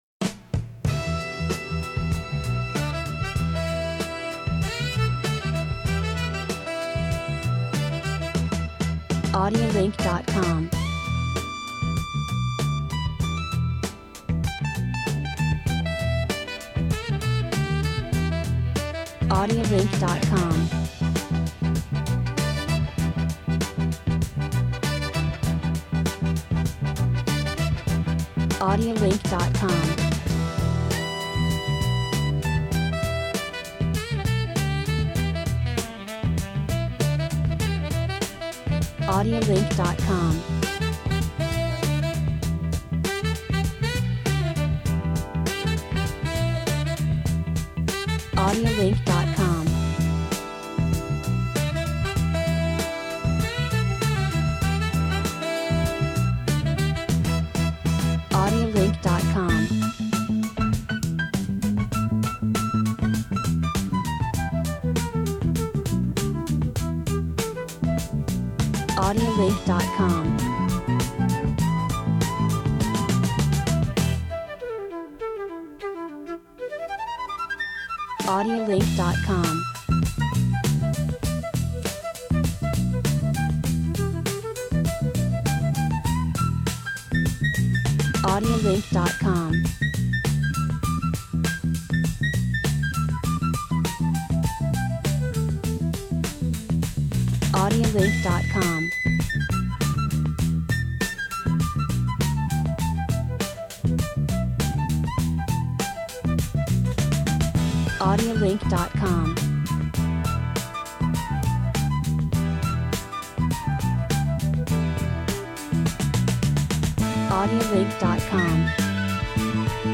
90’s Old Style Dance Music